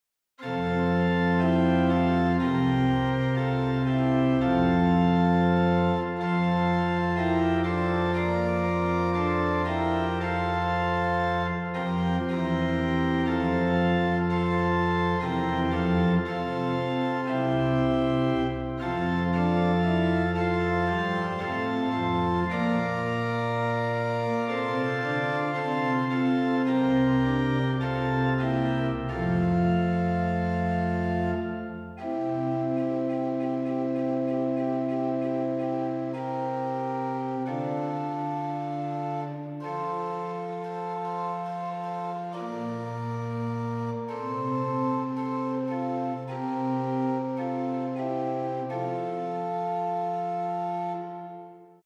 Posnetki z(+) in brez uvoda